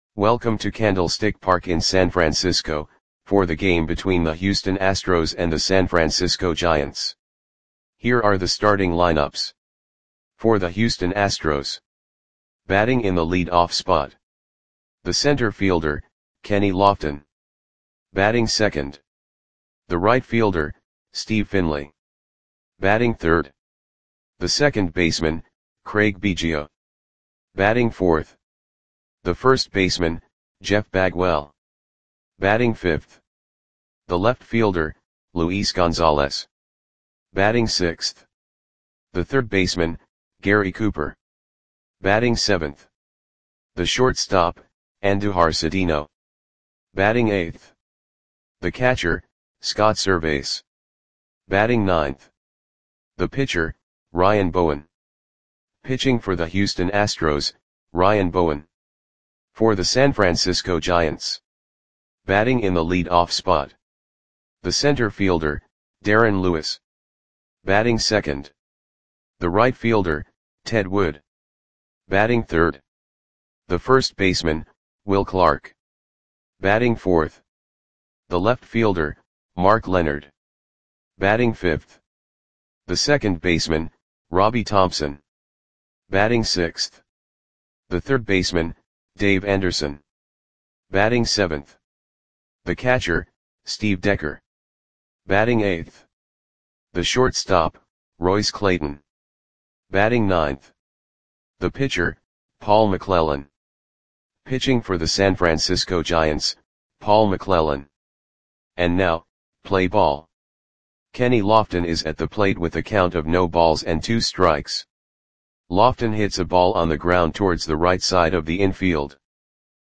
Audio Play-by-Play for San Francisco Giants on October 2, 1991
Click the button below to listen to the audio play-by-play.